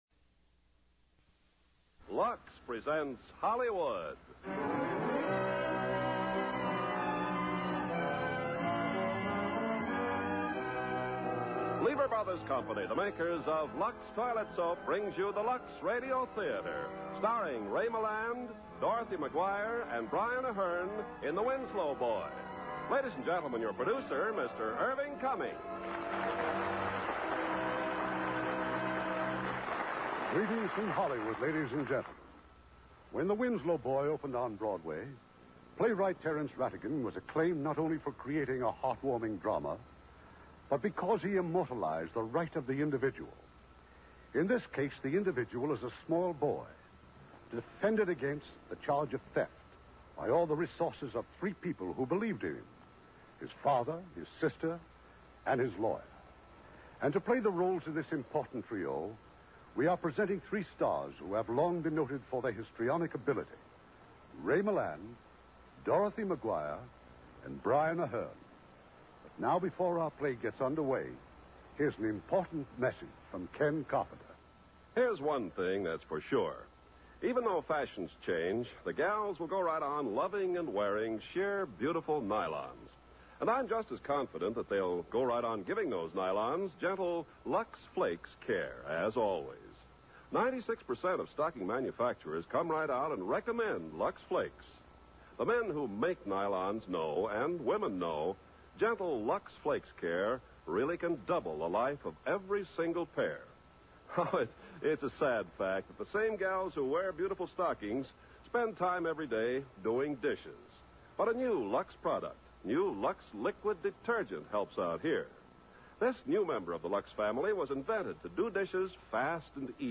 Lux Radio Theater Radio Show
The Winslow Boy, starring Ray Milland, Brian Aherne, Dorothy McGuire